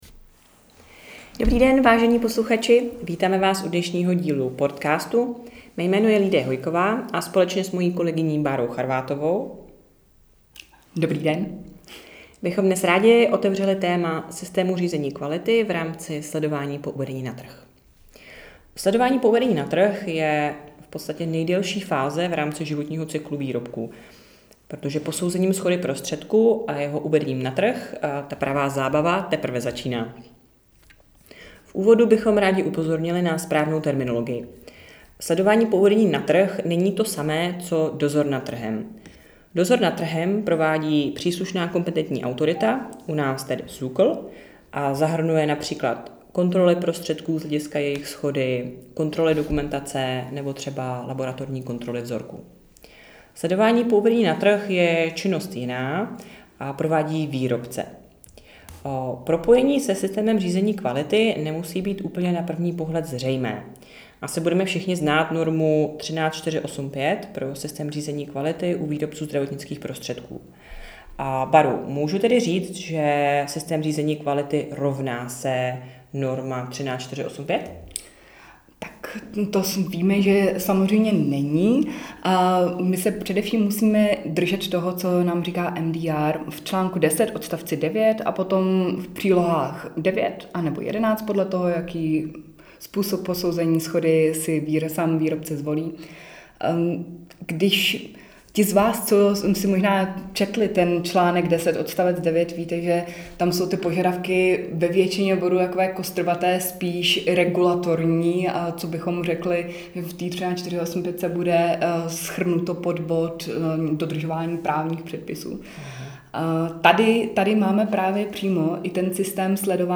Cílem cca desetiminutového rozhovoru je posluchačům stručně vysvětlit důležitost propojení PMS se systémem řízení kvality a nejčastější chyby, kterých si u výrobců zdravotnických prostředků v této oblasti všímáme.